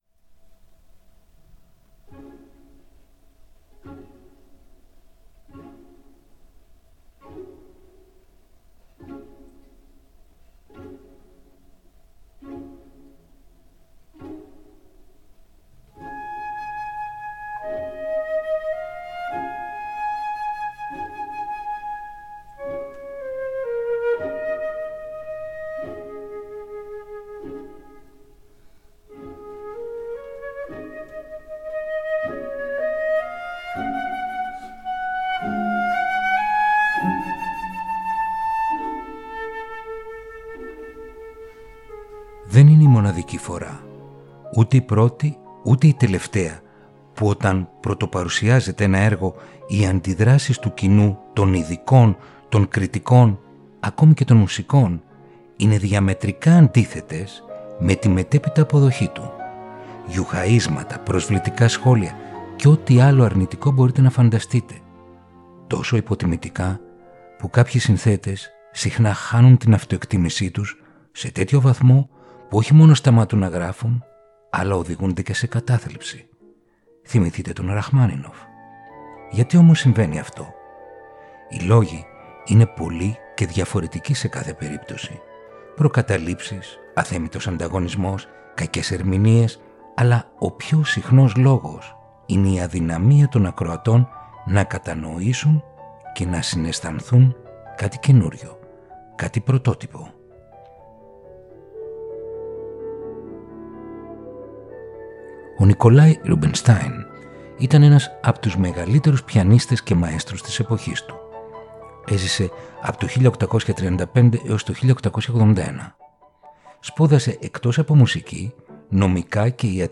Ρομαντικά κοντσέρτα για πιάνο – Επεισόδιο 5ο